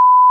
beep.wav